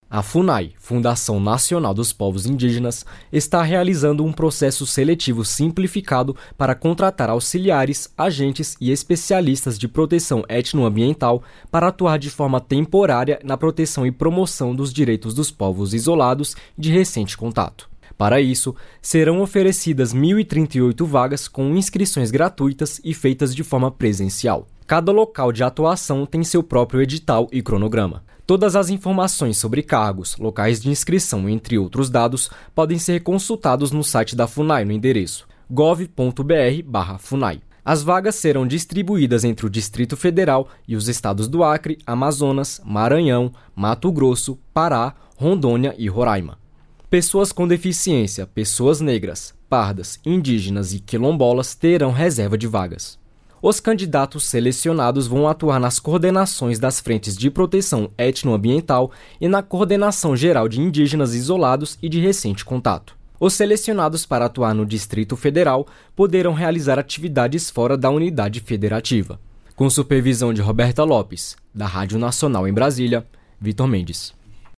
Cerca de mil indígenas protestaram em frente à Biblioteca Nacional de Brasília, área central da cidade, com danças e cantos de diferentes povos. A mobilização foi organizada pela Articulação dos Povos Indígenas do Brasil (Apib) e pelo Conselho Indigenista Missionário (Cimi).